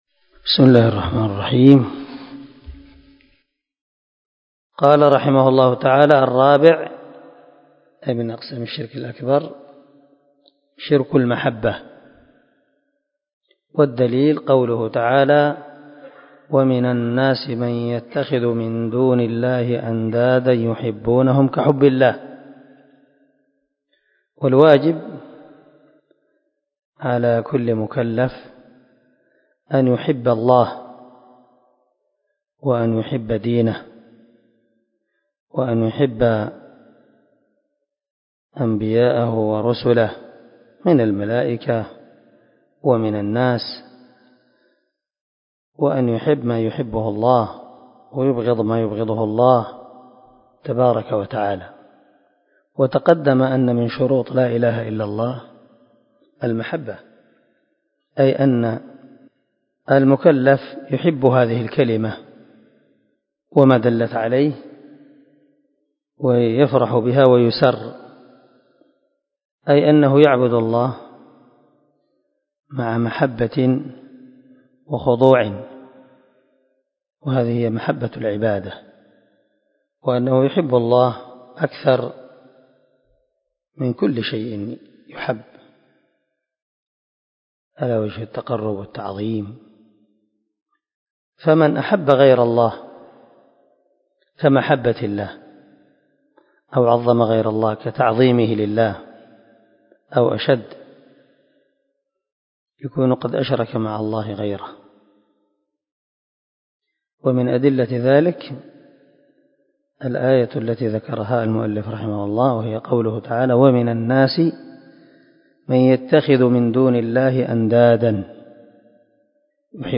🔊الدرس 28 تابع لأنواع الشرك الأكبر
الدرس-28-تابع-لأنواع-الشرك-الأكبر.mp3